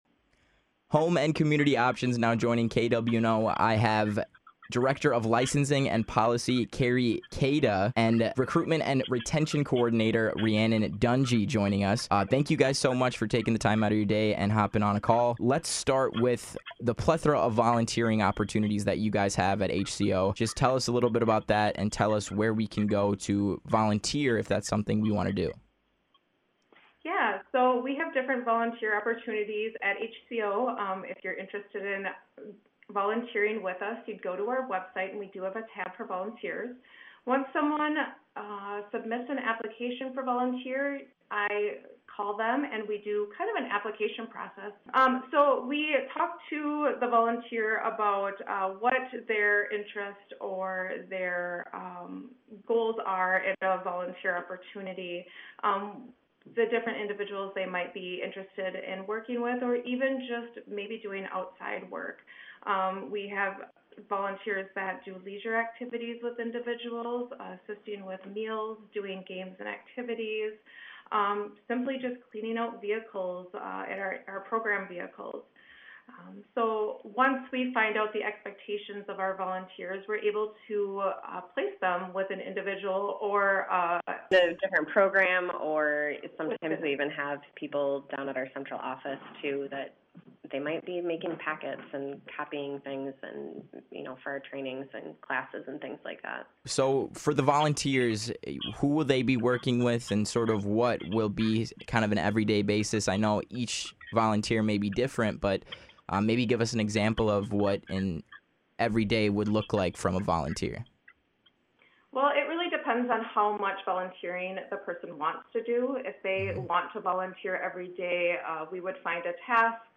(KWNO)- Two representatives from Winona Home and Community Options joined KWNO to chat about the plethora of volunteer and internship opportunities that HCO has to offer.
hco-interview-e-4-25.mp3